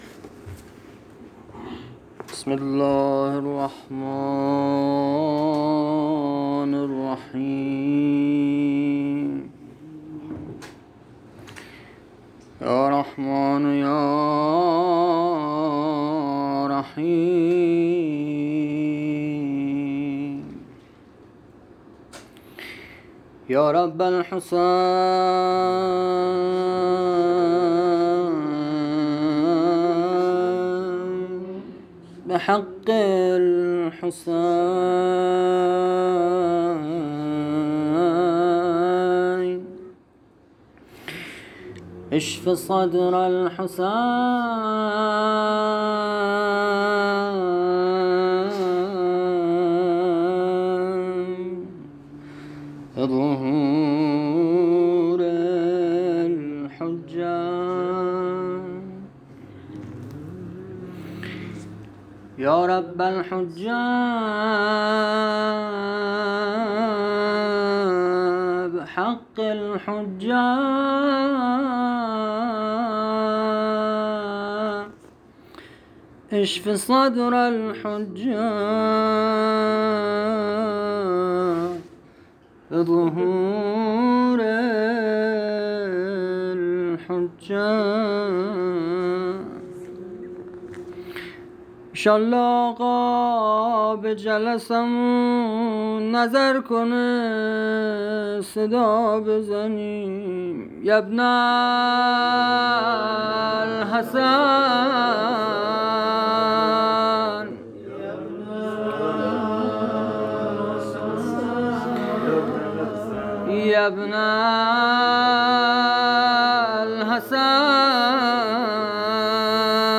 [فایل صوتی] - [روضه] - [حضرت رقیه(س)] - از کودکی‌اش فاضله و عالمه بود....